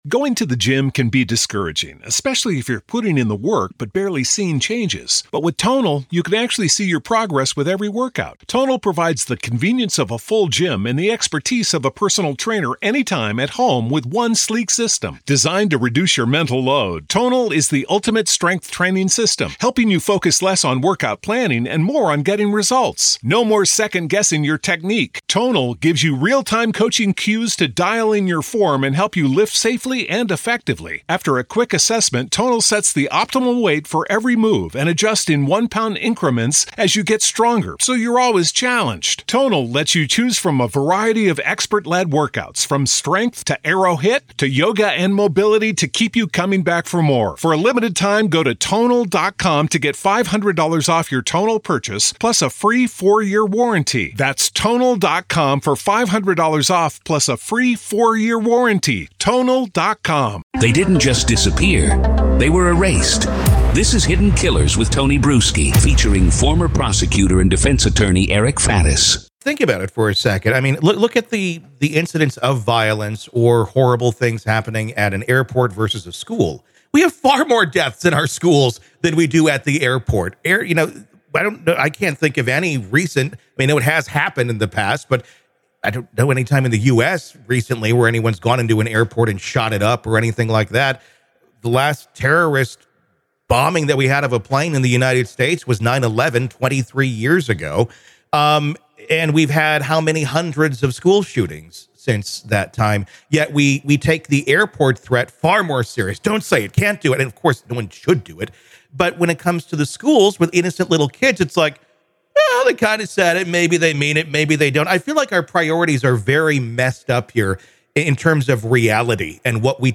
The conversation reflects on how laws, like the Red Flag Law, offer potential solutions but come with challenges in implementation and potential for abuse. They conclude that while no single solution is apparent, collaborative efforts between parents, schools, and authorities could help prevent future tragedies.